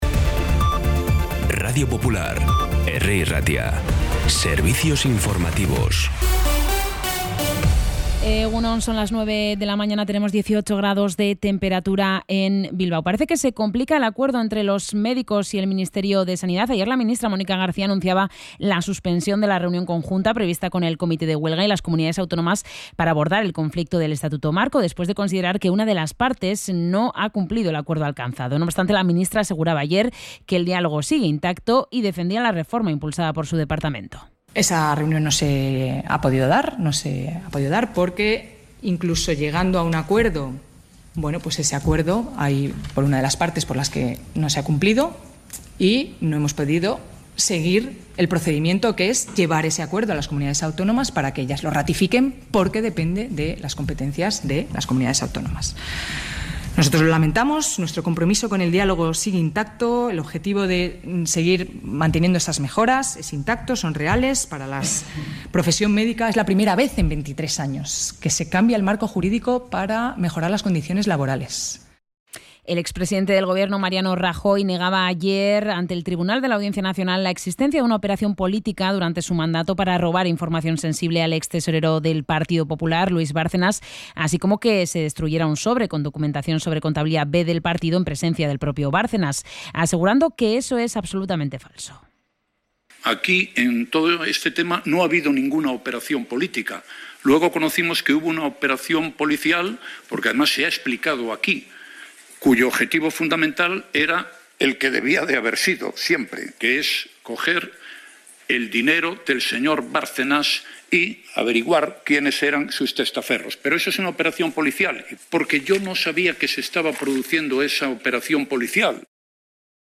Podcast Informativos
Los titulares actualizados con las voces del día.